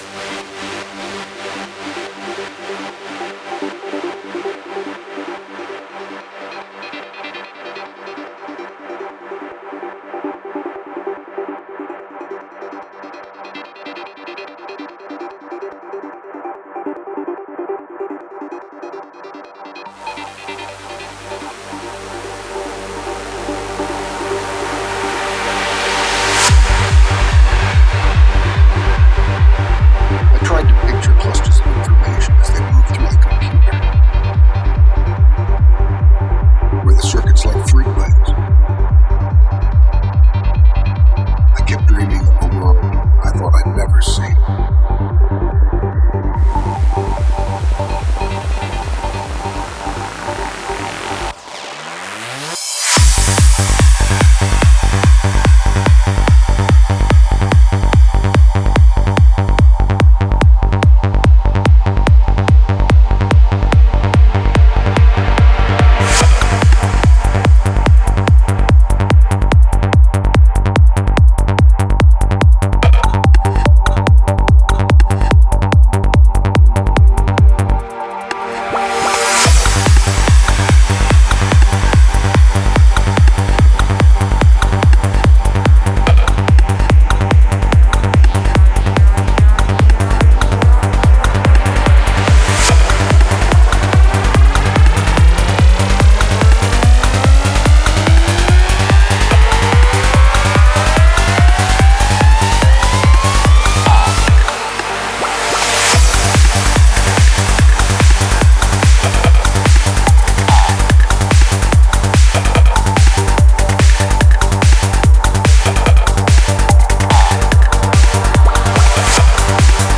Назад в Psy-Trance
Style: Psytrance